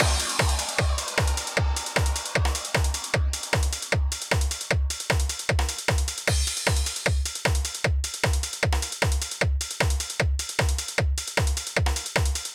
ドラム・FX
絶妙なふくらみがあるのが特徴だと思っています。
今回は16分裏にも配置する事で、一捻りあるリズムにしています。